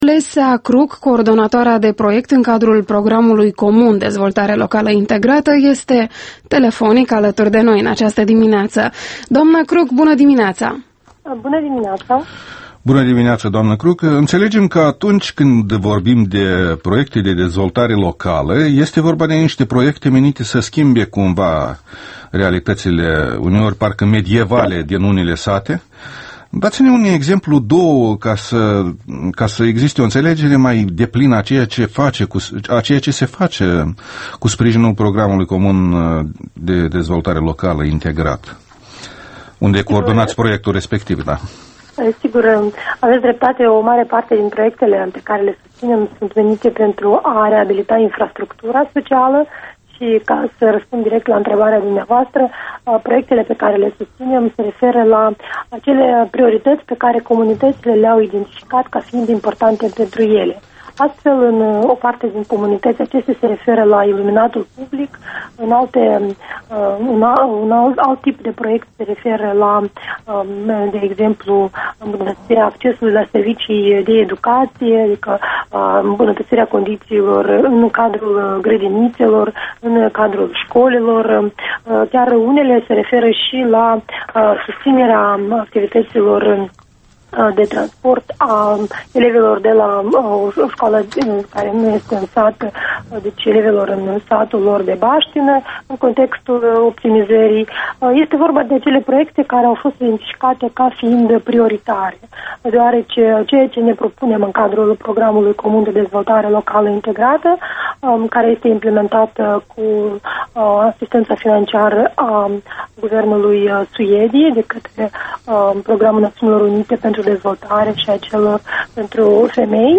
Embed Interviul dimineții la Europa Liberă